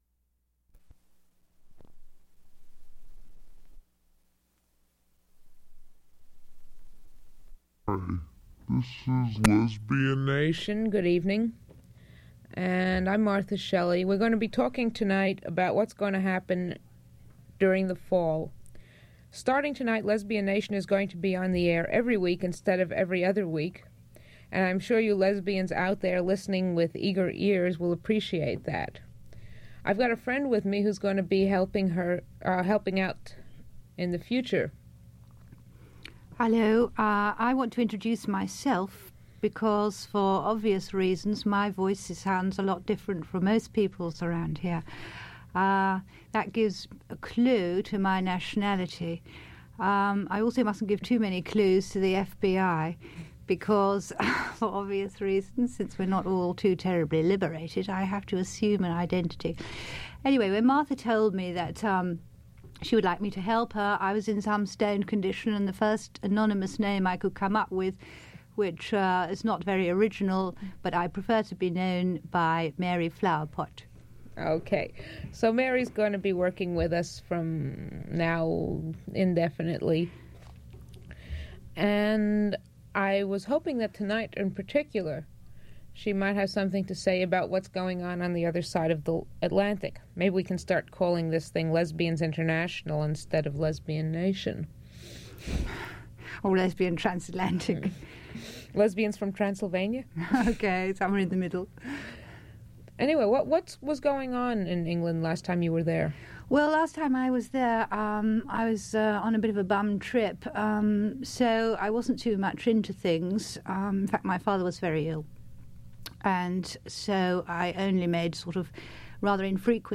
Physical Format 1/4 inch audio tape Collection Lesbian Nation radio program